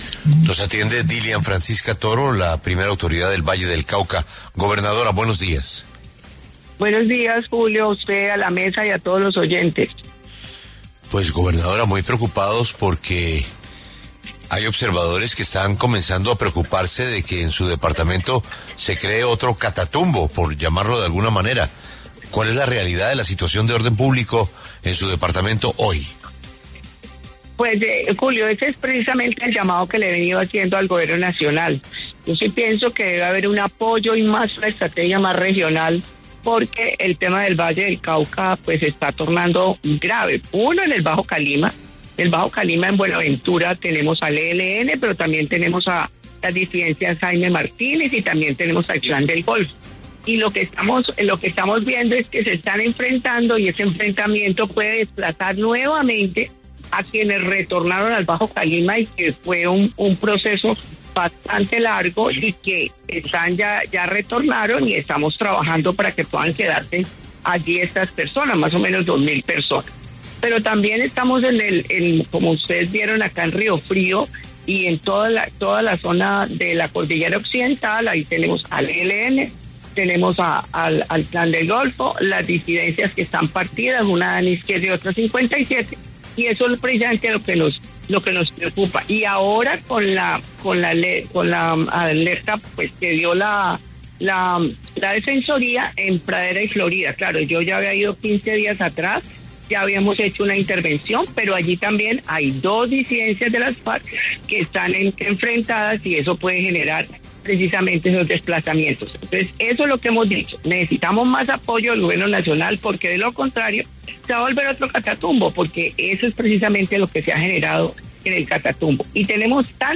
En entrevista con La W, la gobernadora del Valle del Cauca, Dilian Francisca Toro, reiteró el llamado urgente al Gobierno Nacional tras la reciente alerta temprana emitida por la Defensoría del Pueblo, advirtiendo sobre el riesgo de desplazamientos forzados y violaciones a los derechos humanos en los municipios de Pradera y Florida, debido a los enfrentamientos entre disidencias de las Farc.